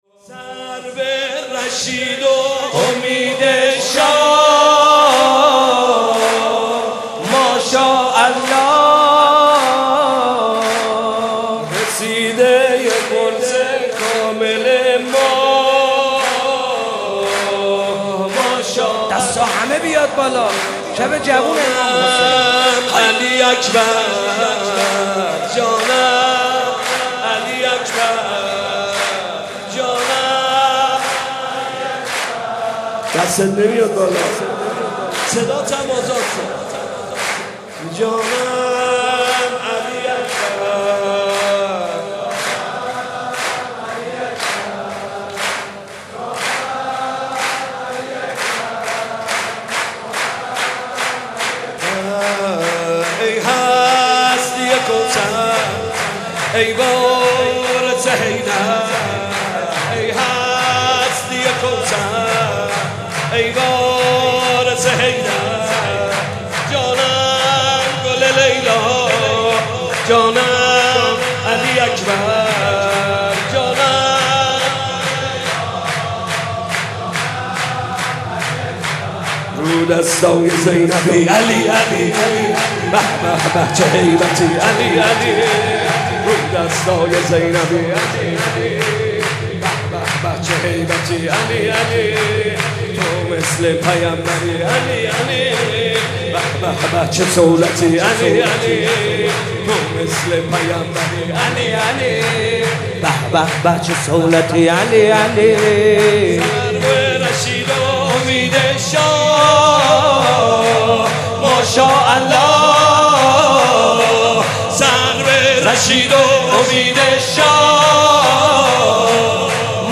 سرود جدید